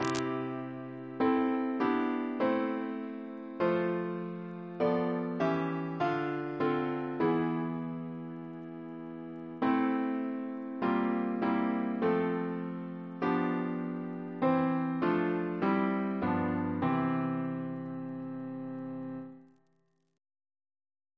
Double chant in C Composer: Walter Parratt (1841-1924) Reference psalters: ACP: 222; RSCM: 108